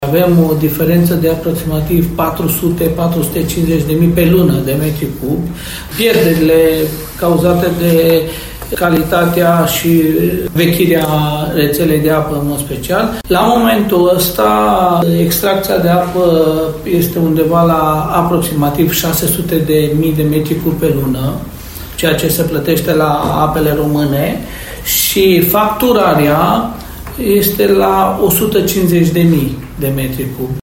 Cauza este una cunoscută: rețeaua de țevi vechi și ruginite care se sparg constant și generează pierderi uriașe, spune primarul municipiului Lugoj Calin Dobra.